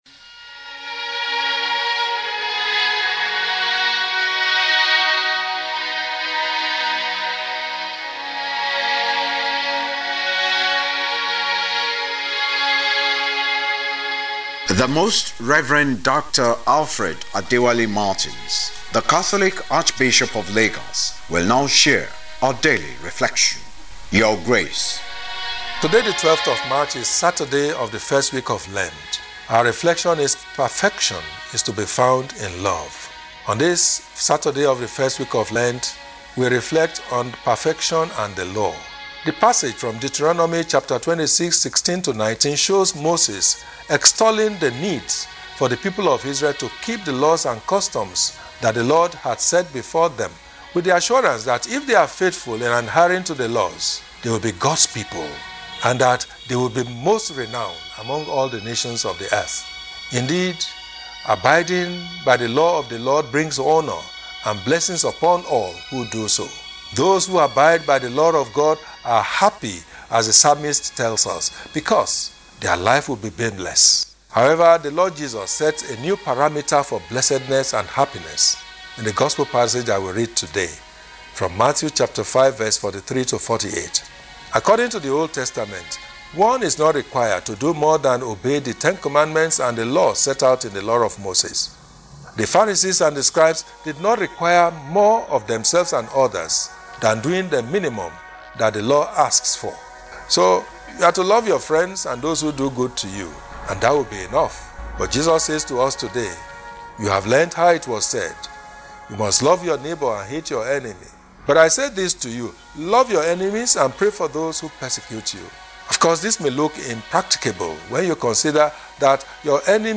LENTEN REFLECTION WITH ARCHBISHOP MARTINS.
LENTEN-Talk-Saturday-.mp3